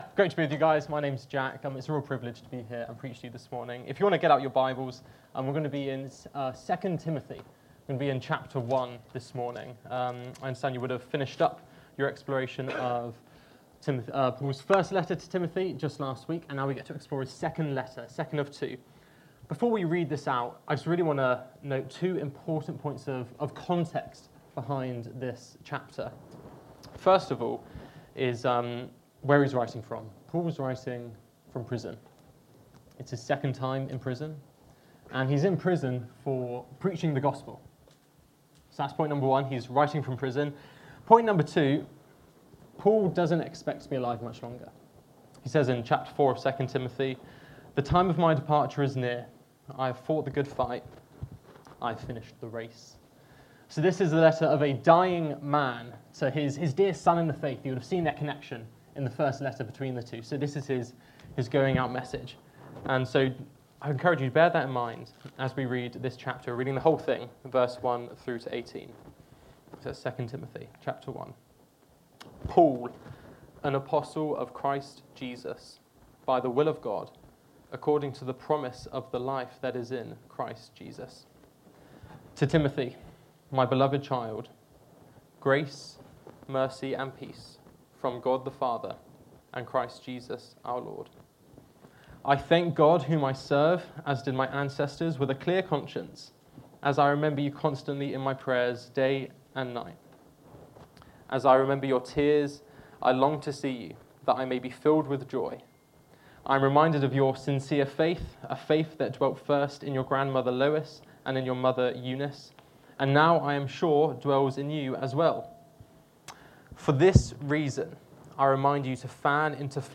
Download Loyalty To The Gospel | Sermons at Trinity Church